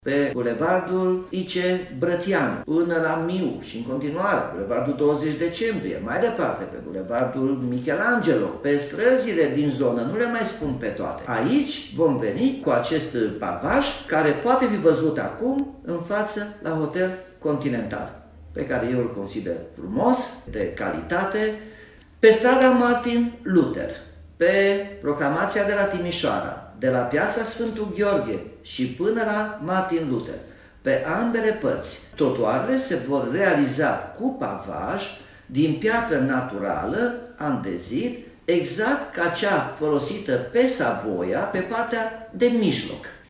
Modelul a cărui aplicare a stârnit controverse în mediul on-line va apărea, în schimb, în alte zone, după cum explică primarul Nicolae Robu: